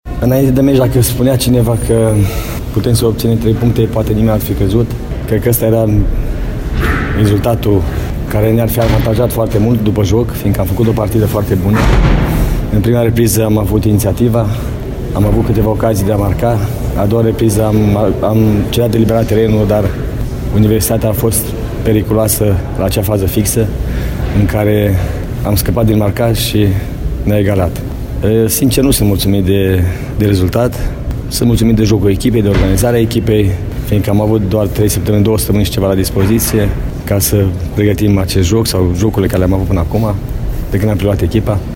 Antrenorul bănățenilor, Dorinel Munteanu: